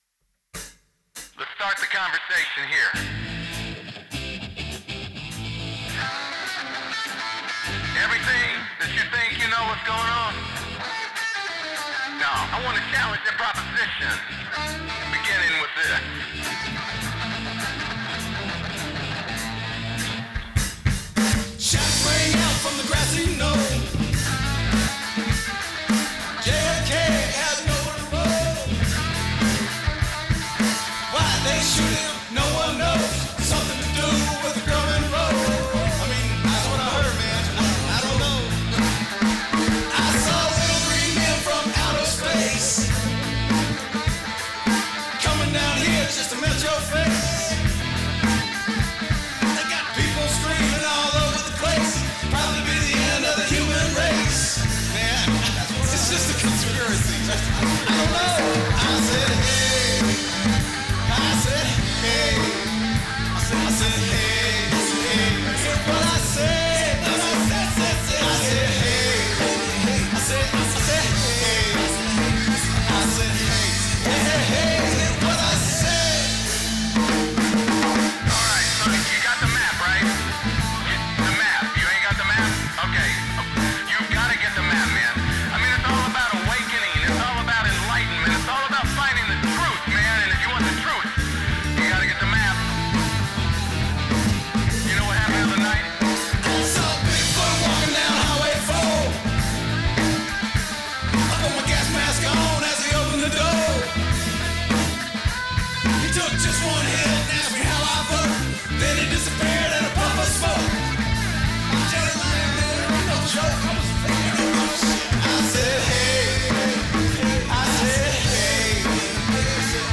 Feb 14, 2023 | indie rock, songs, unsigned
He hands you the EP song list and points to the middle song – Conspiracy – which they describe as a punk-tribute, borderline novelty song… and a situational potential suddenly pops into your head.
• Best mixdown we can make of the song (we are clearly amateur DIY)